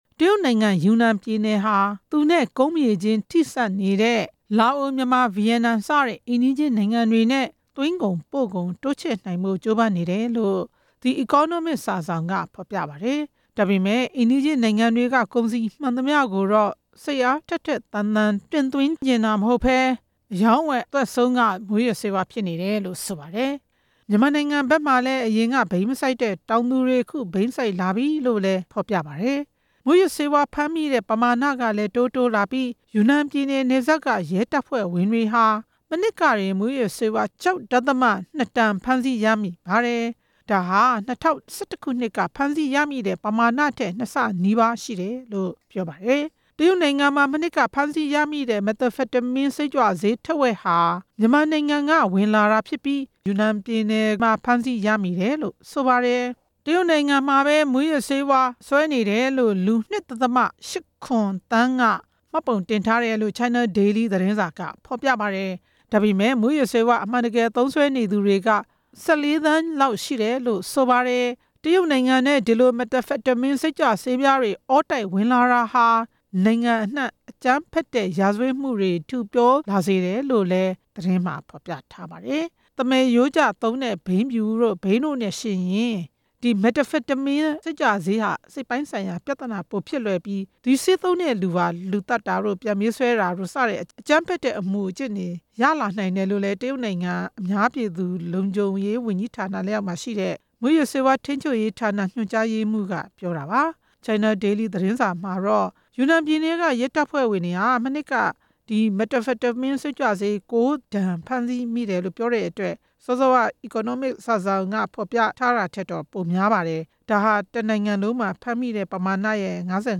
စုစည်းတင်ပြချက်ကို နားဆင်နိုင်ပါပြီ။